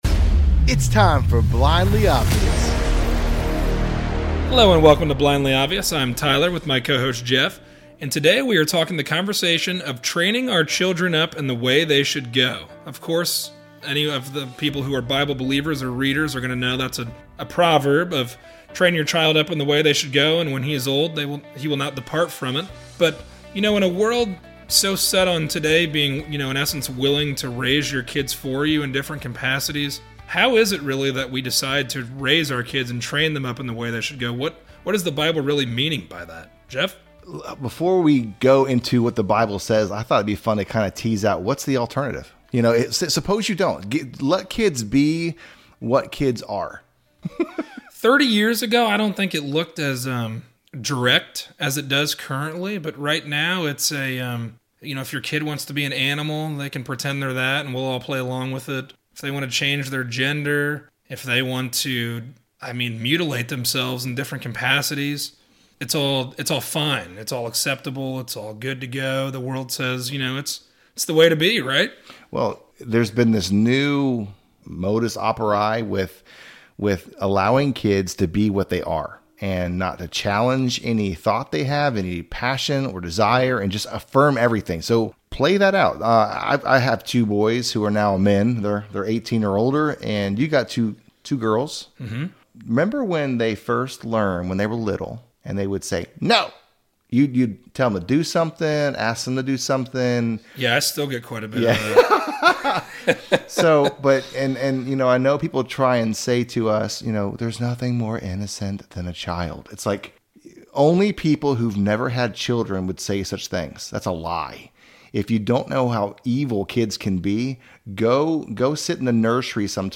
A conversation on training up our children for life success. Are we going to step up in our given role as parents or let the world raise our children in its ways?